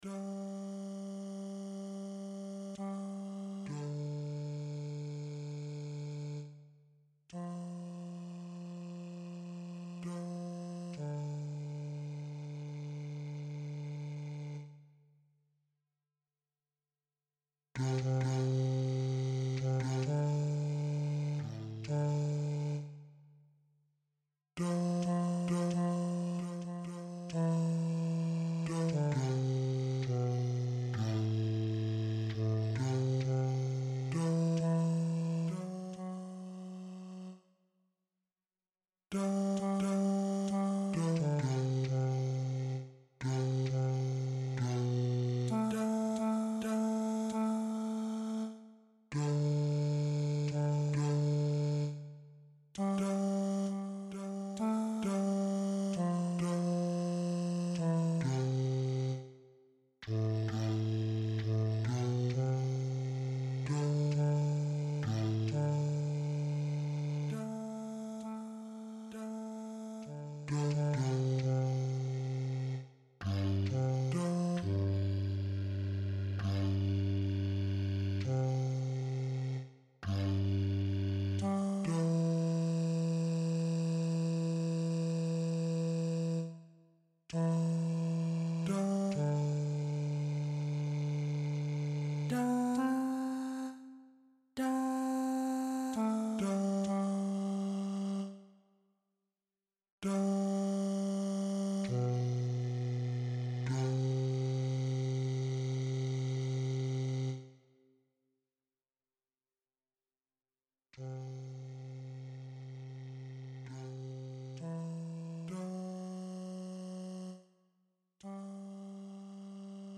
Come-What-May-Bass-V3.mp3